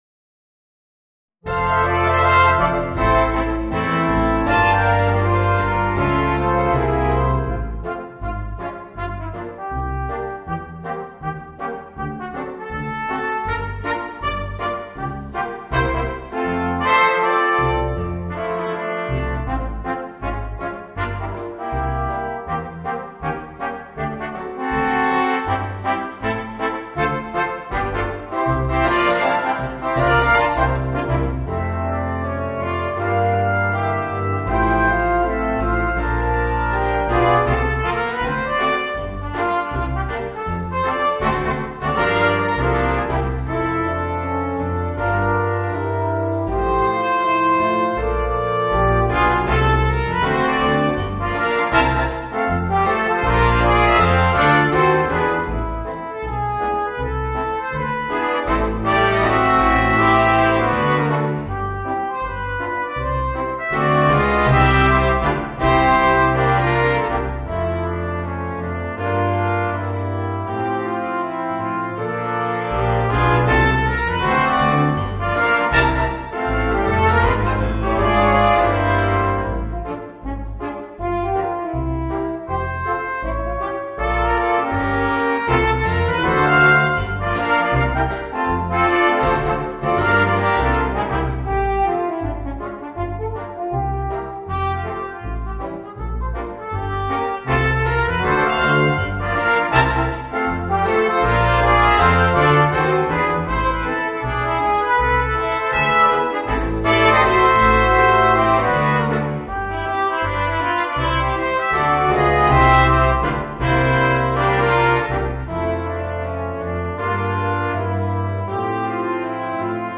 Voicing: 2 Trumpets, Horn and 2 Trombones